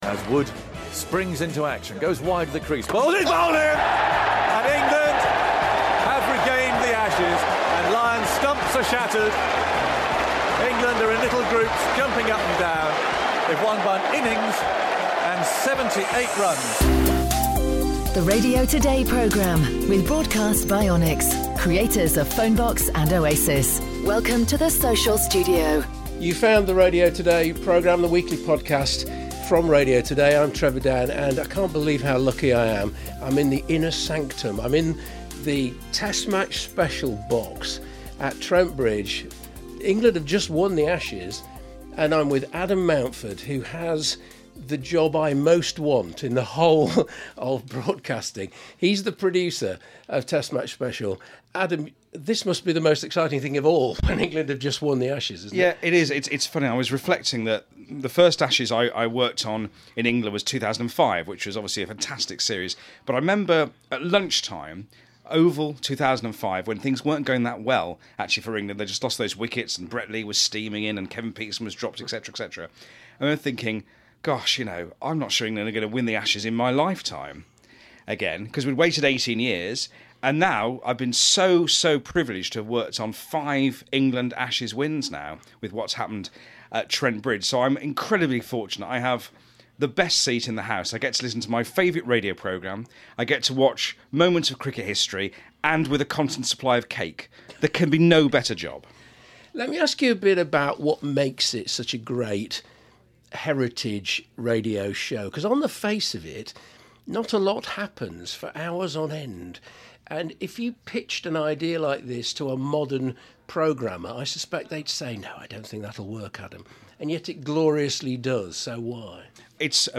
in the BBC Test Match Special studio at Trent Bridge